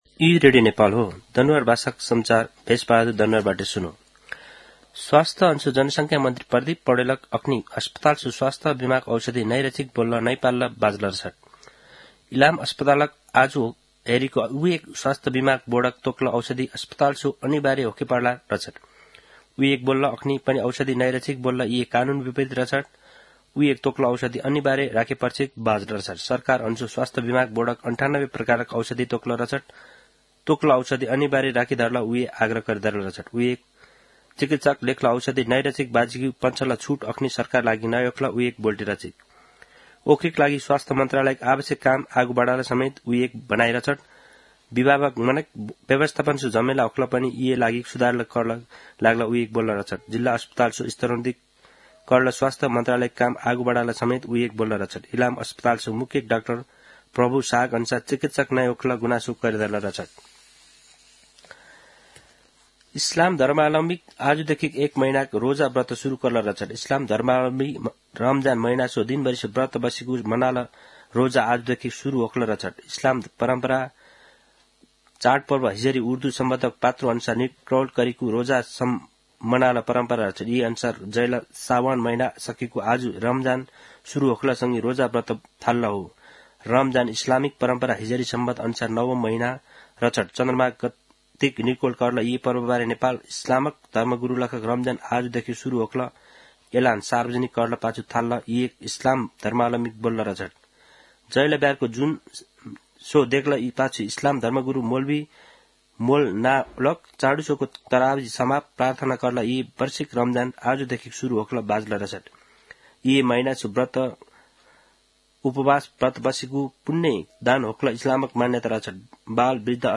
दनुवार भाषामा समाचार : १९ फागुन , २०८१
danuwar-news-.mp3